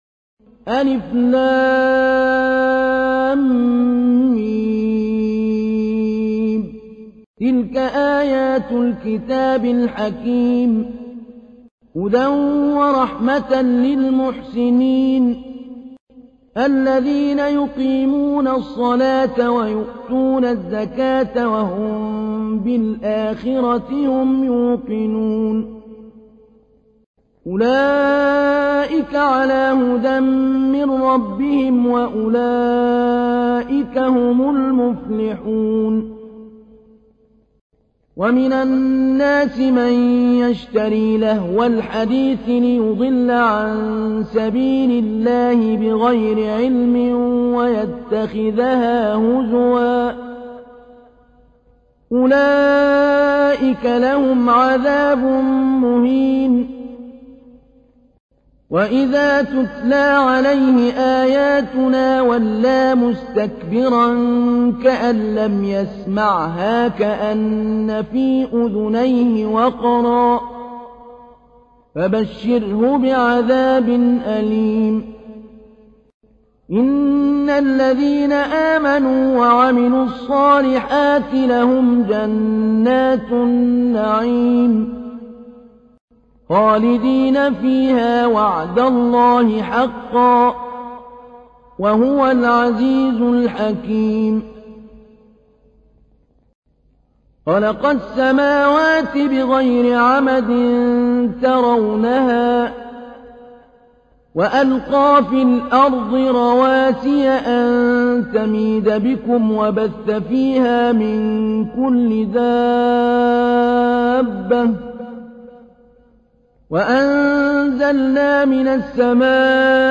تحميل : 31. سورة لقمان / القارئ محمود علي البنا / القرآن الكريم / موقع يا حسين